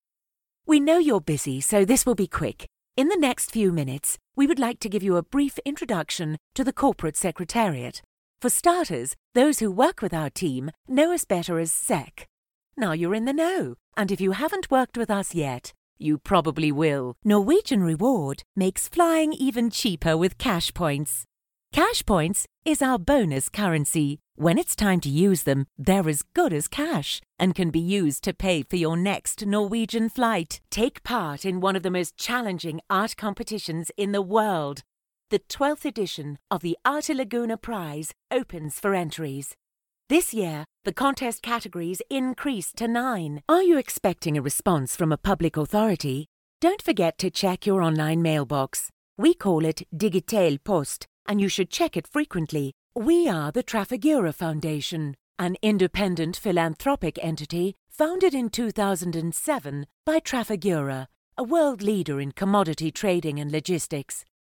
E=learning, Corporate & Industrial Voice Overs
English (South African)
Adult (30-50) | Older Sound (50+)